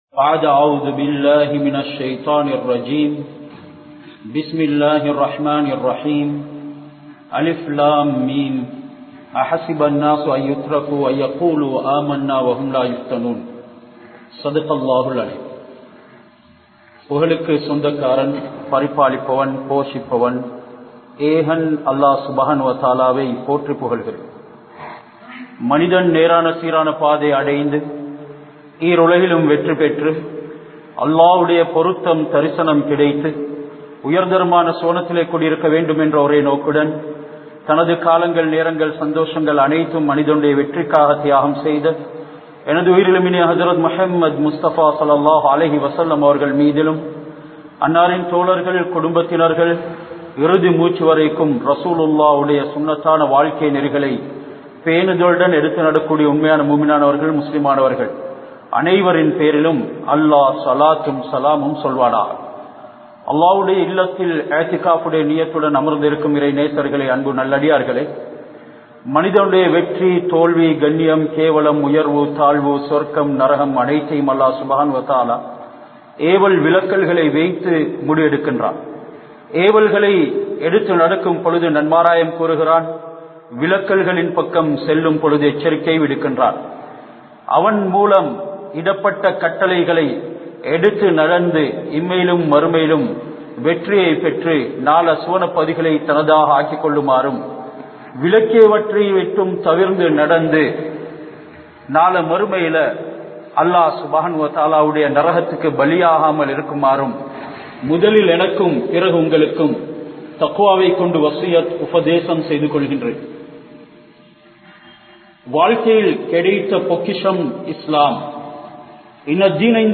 Islamum Inraiya Fithnavudaiya Kaalamum(இஸ்லாமும் பித்னாவுடைய காலமும்) | Audio Bayans | All Ceylon Muslim Youth Community | Addalaichenai
Delgahagoda Jumua Masjidh